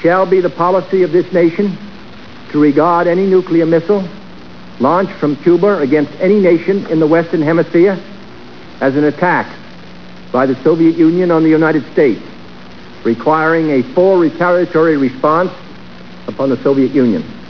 chilling speech made to the nation.